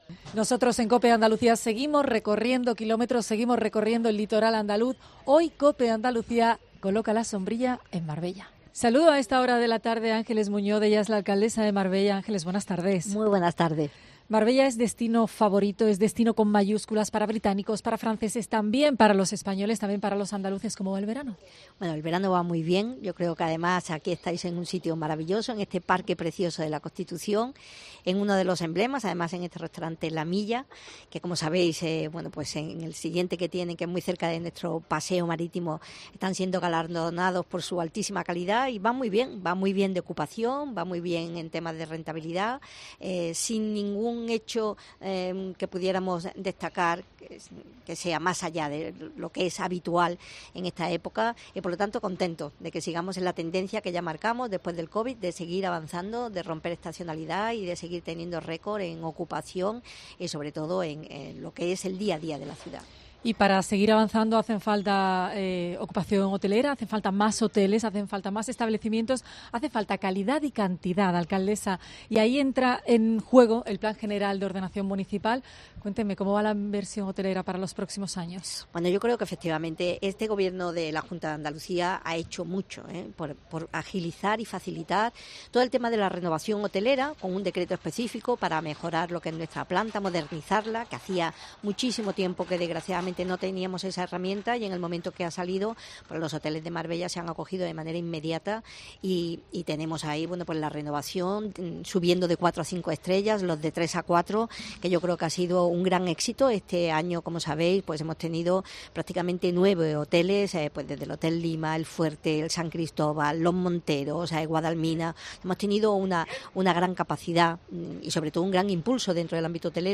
Sobre empleo, sobre turismo, y por supuesto sobre playas y demás atractivos turísticos hablamos con la alcaldesa de la ciudad, Ángeles Muñoz, que ha revalidado la mayoría absoluta en las últimas elecciones municipales.